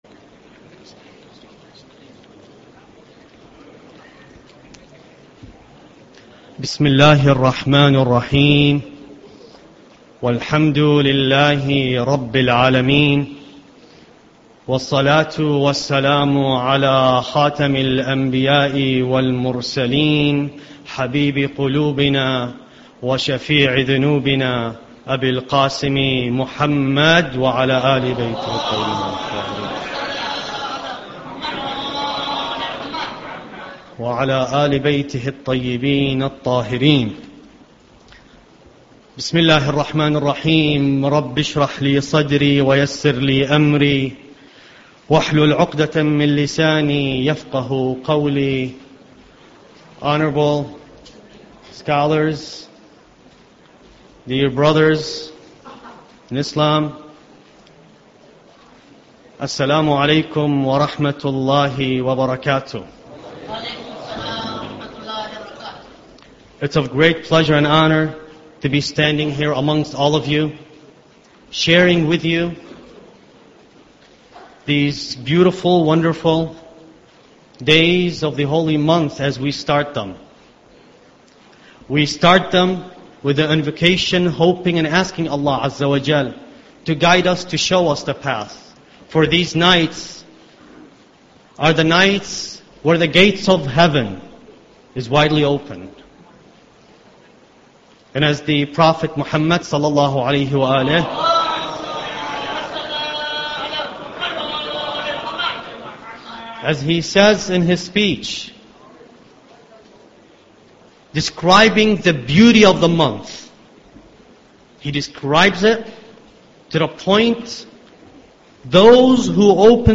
Ramadan Lecture 1